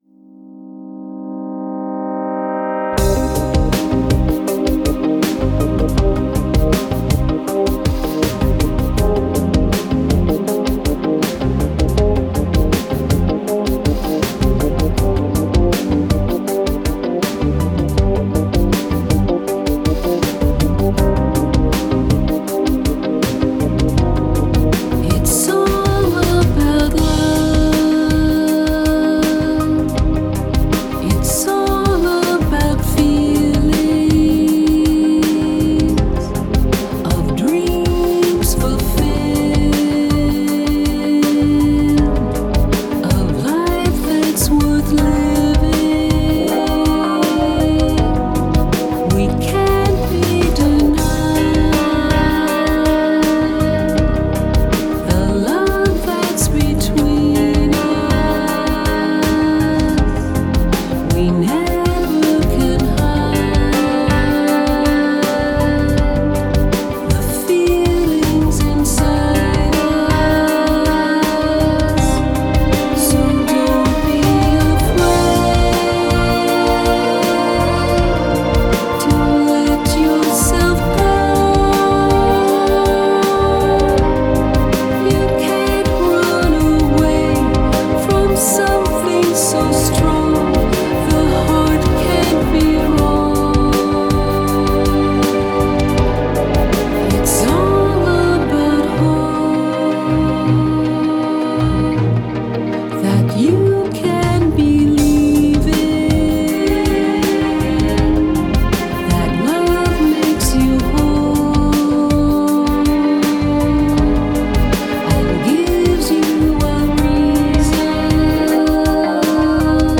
Genre: Jazz/Pop Vocals